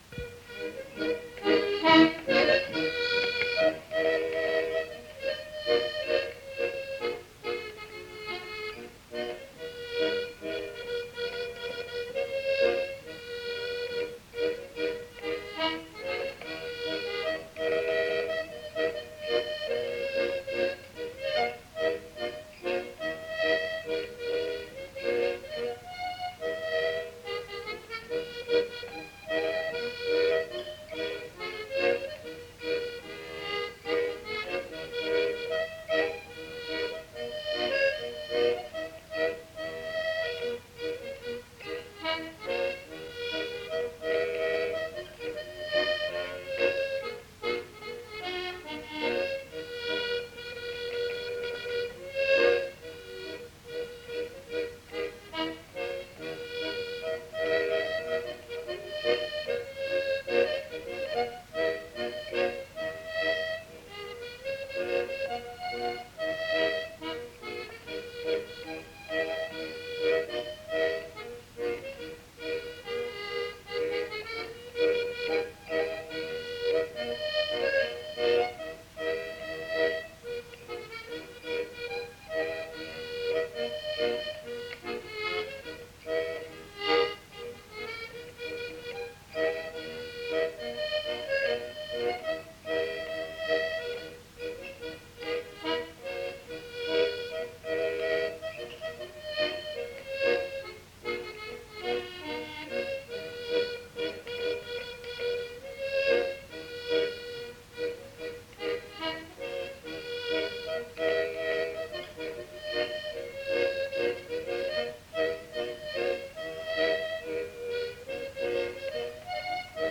Aire culturelle : Cabardès
Lieu : Villardonnel
Genre : morceau instrumental
Instrument de musique : accordéon diatonique
Danse : mazurka